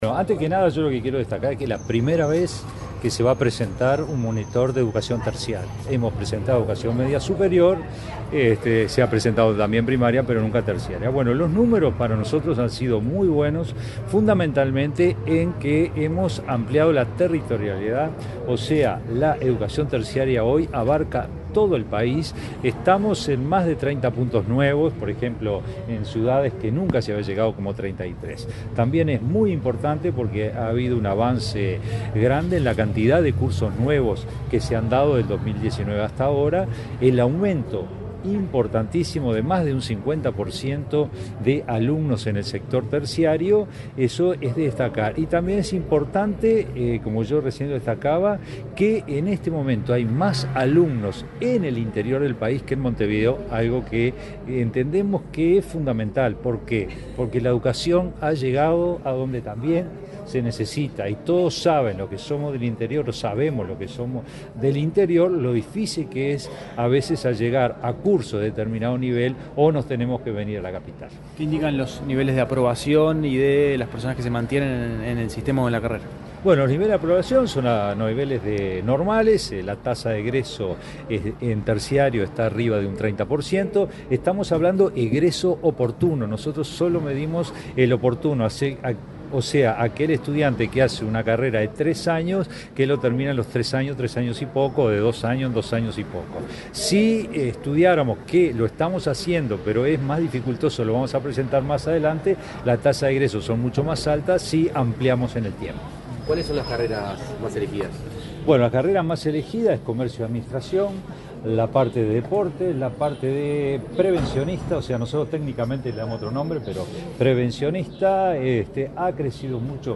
Declaraciones del director general de UTU, Juan Pereyra
Este martes 3 en Montevideo, el titular de la Dirección General de Educación Técnico-Profesional (DGETP-UTU), Juan Pereyra, dialogó con la prensa,